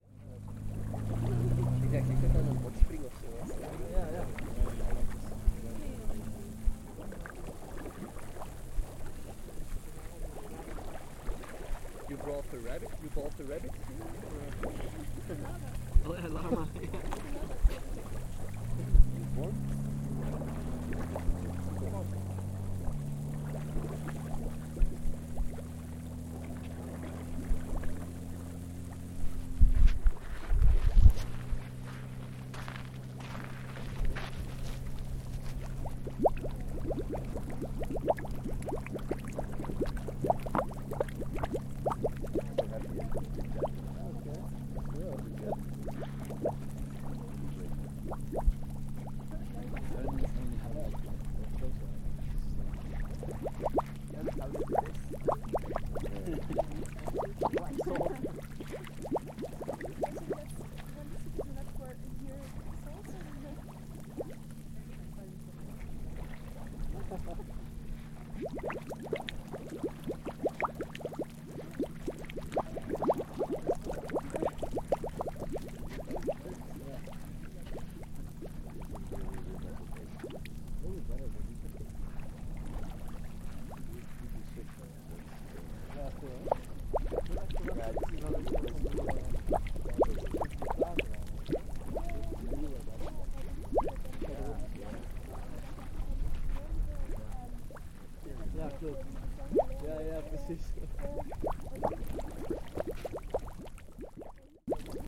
Bubbling spring on the Bolivian salt flats
Field recording of a bubbling spring of cold salt water taken in the Salar de Uyuni, Bolivia.
Altitude: 3,664.52 m (12,022.71 ft)